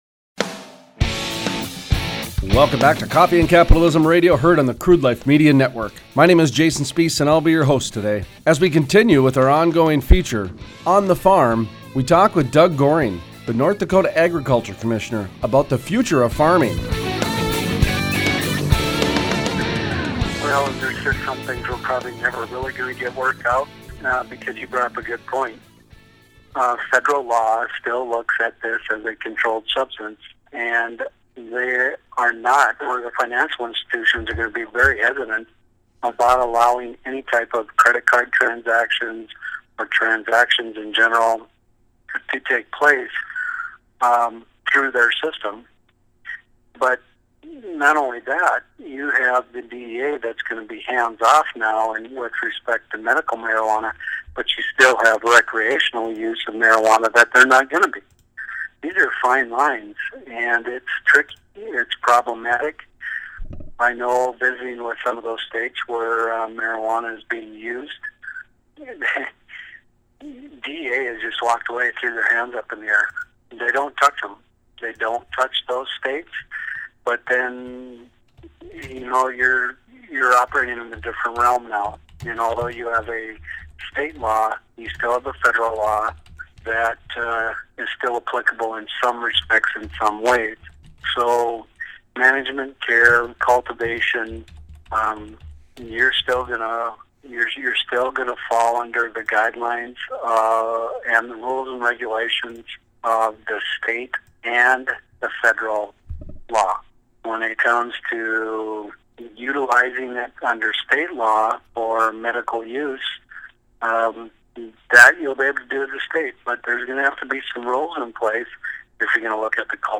Interviews: Doug Goehring, ND Agriculture Commissioner Gives an update on the Compassionate Care Act and how the state will oversee the growing of cannabis. From permits to seeds to harvest, many topics are discussed.